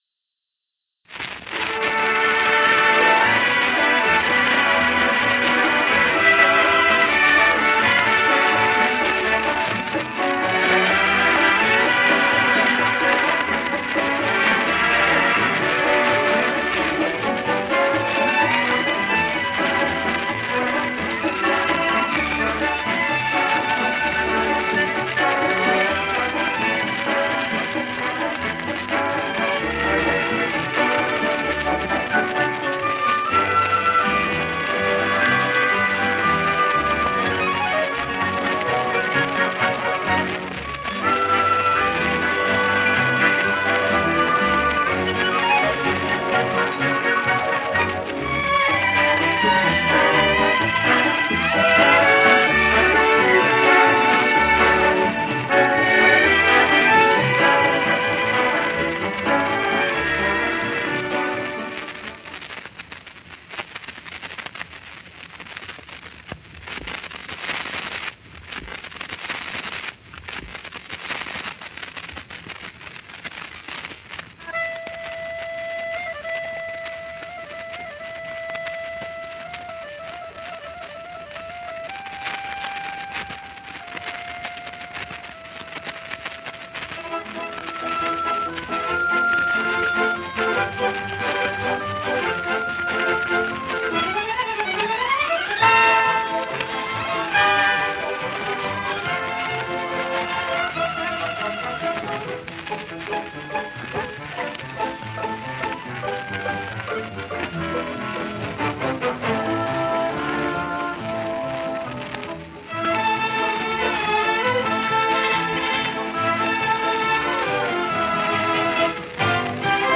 oldRadio-latin.wav